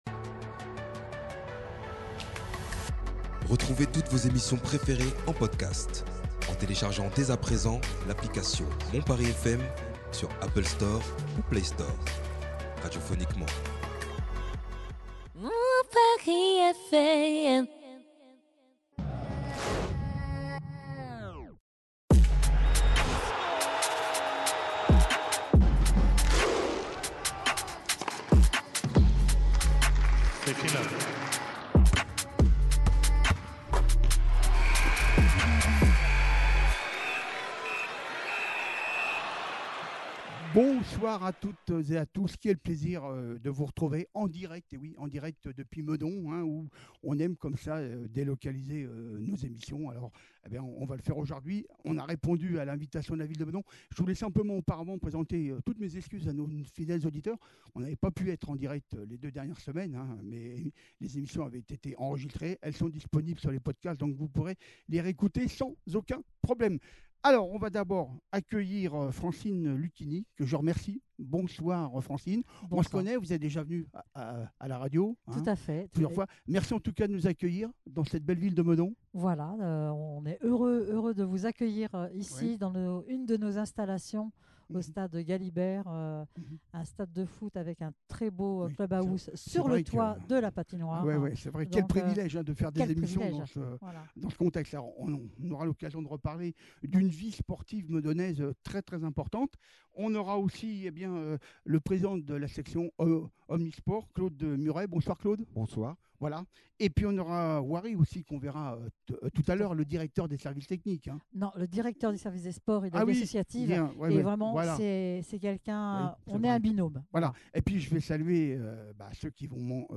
Nous serons en direct depuis Meudon afin de pénétrer dans l’intimité d’une des villes les plus dynamiques et actives en matière de sport. Plusieurs associations sportives viendront se raconter à notre micro. Nous finirons , bien sûr par une importante page Foot qui reviendra sur la belle semaine de nos clubs français engagés dans les différentes compétitions européennes .